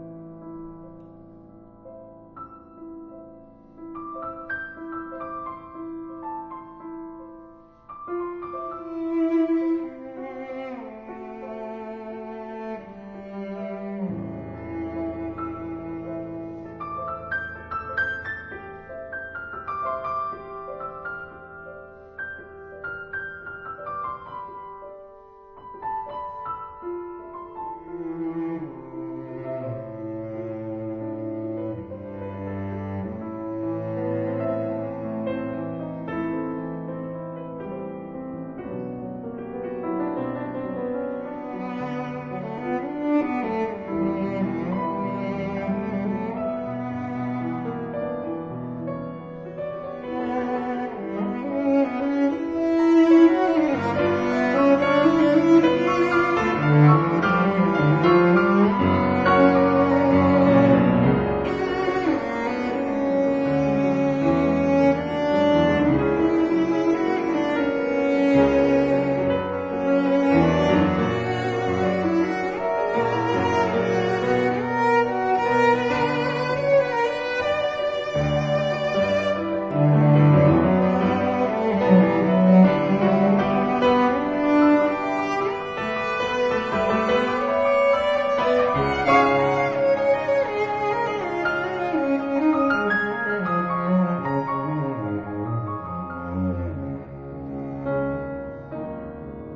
曲子有種創造性的感受，感覺大於創作年紀。